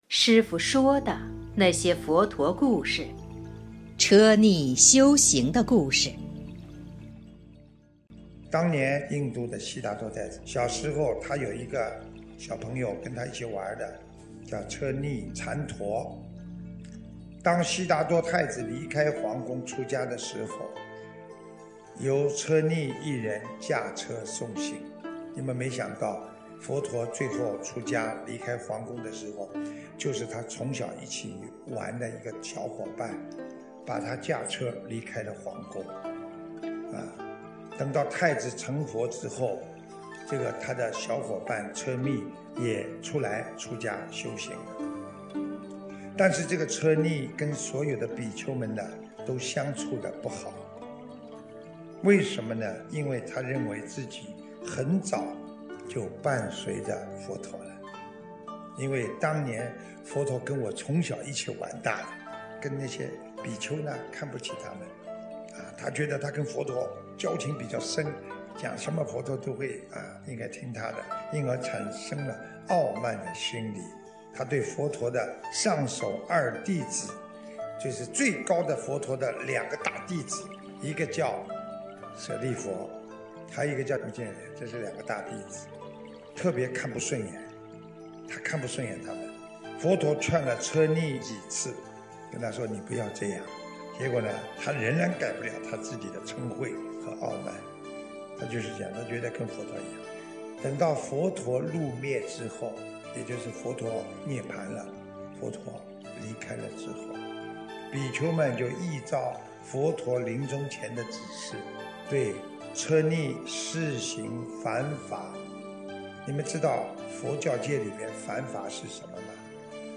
（后附师父解说）！2020年01月22日【师父原声音】 ...